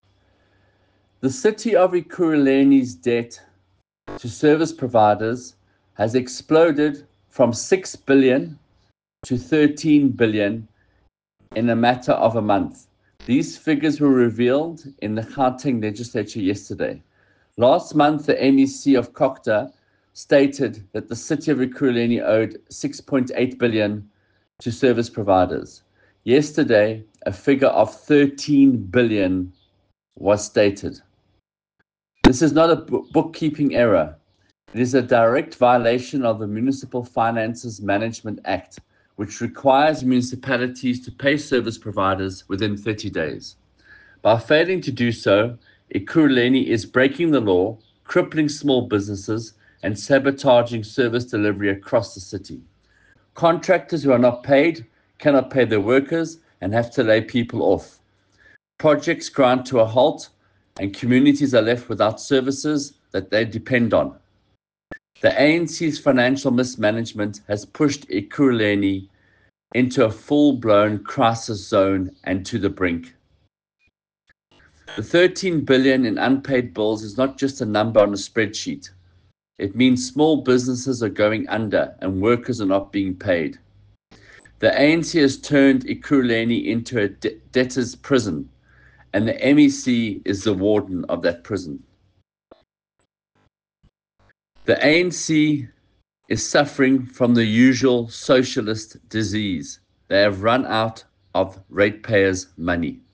soundbite by Mike Waters MPL.